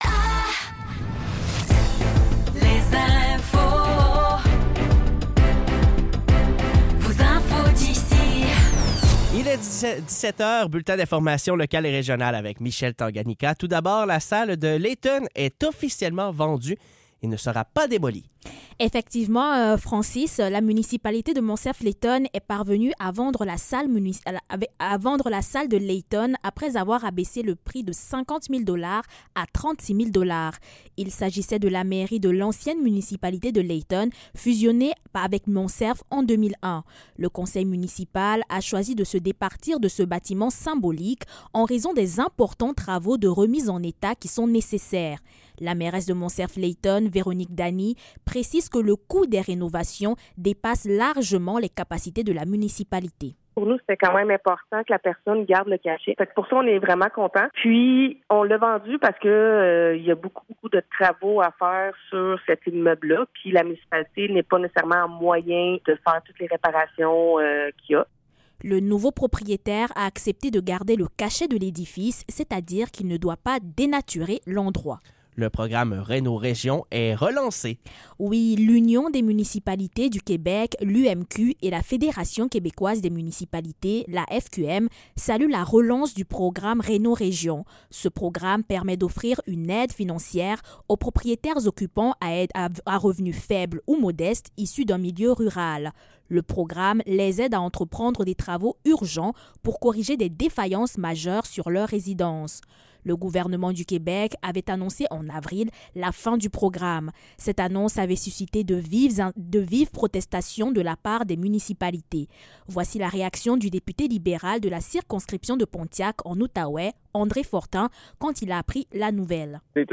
Nouvelles locales - 12 mai 2025 - 17 h